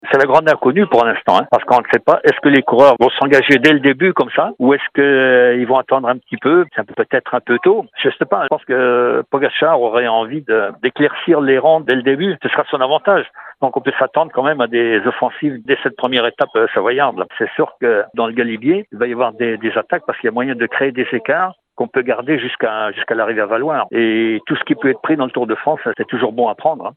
Les coureurs vont-ils devoir s’employer dès le début du Tour de France ? C’est la question que nous avons posé à l’ancien double vainqueur du Tour de France  Bernard Thévenet :